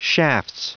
Prononciation du mot shafts en anglais (fichier audio)
Prononciation du mot : shafts